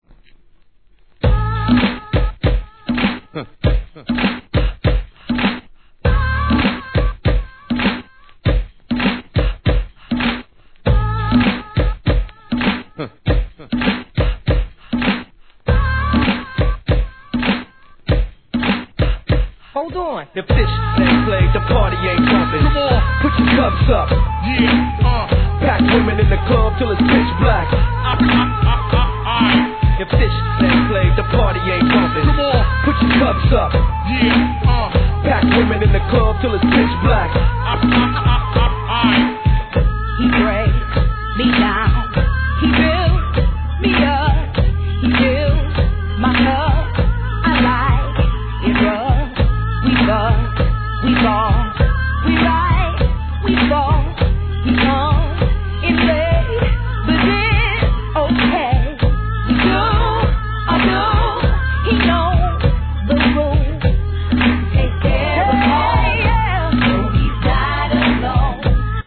HIP HOP/R&B
DJのために繋ぎ易さも考慮されたREMIX人気シリーズ68番!!